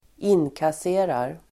Ladda ner uttalet
inkassera verb, collect , cash Grammatikkommentar: A & x Uttal: [²'in:kase:rar] Böjningar: inkasserade, inkasserat, inkassera, inkasserar Användning: även lös sms Definition: ta emot el. driva in pengar
inkasserar.mp3